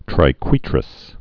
(trī-kwētrəs, -kwĕtrəs)